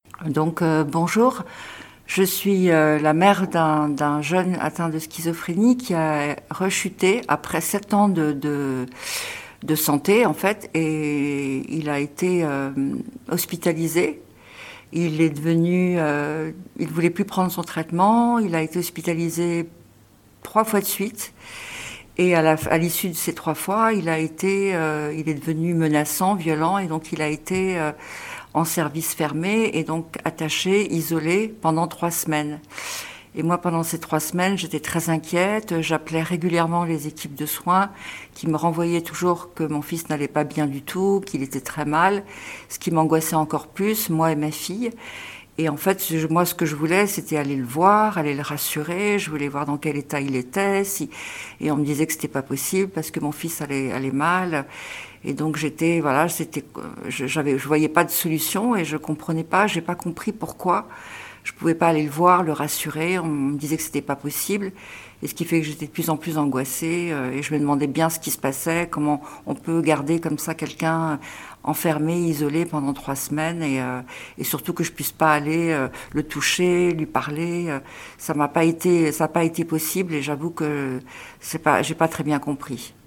Écoutez le récit de cette mère d'un jeune homme hospitalisé sous contrainte.